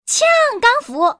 Index of /poker_paodekuai/update/1527/res/sfx/changsha_woman/